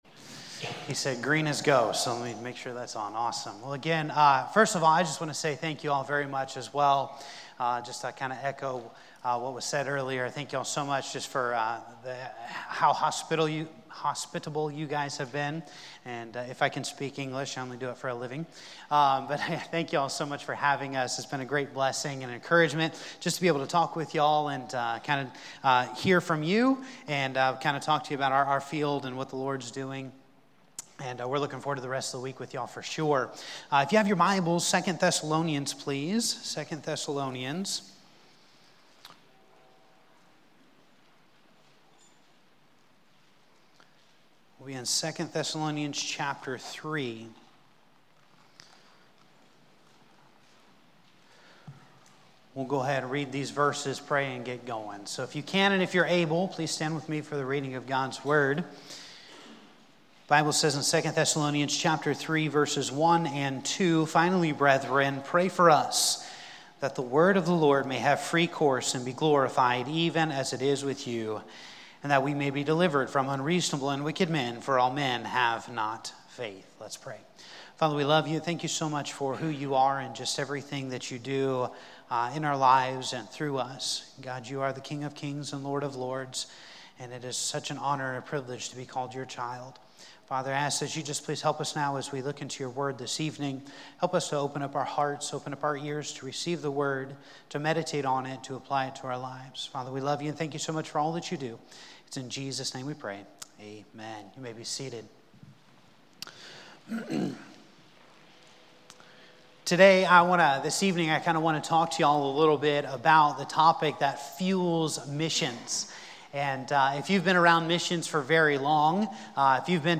Sermons | First Baptist Church
Monday Night Missions Conference 2024